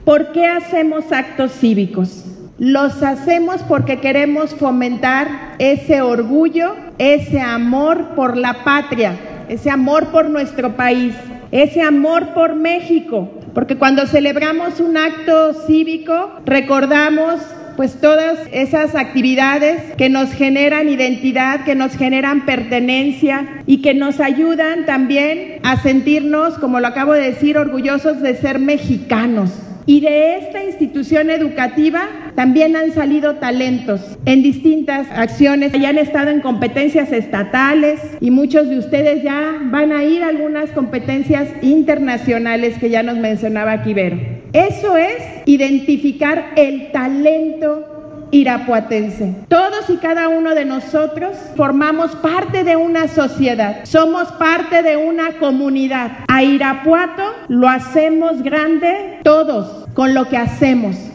AudioBoletines
Lorena Alfaro García – Presidenta Municipal
Juan Luis Saldaña López Delegado Región 4 de la SEG